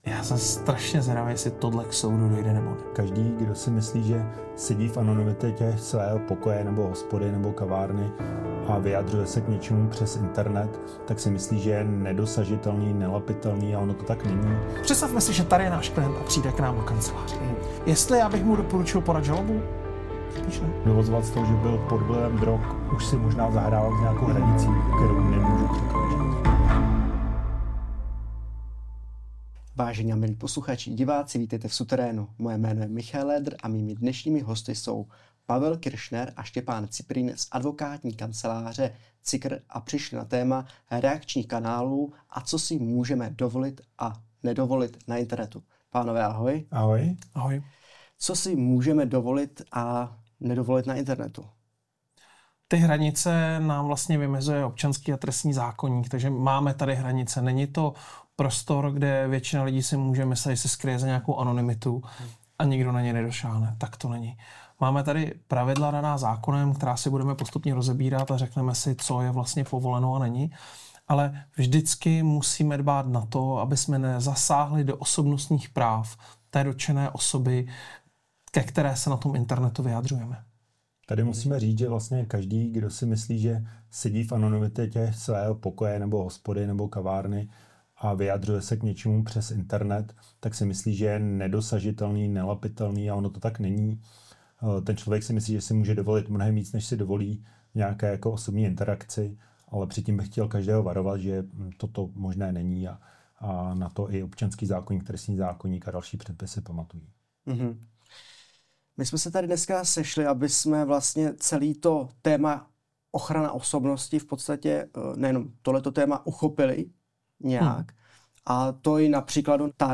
Genres: Comedy, Comedy Interviews